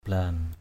/bla:n/ (d.) trục (xe).